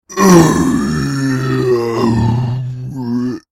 Zombie Growl Halloween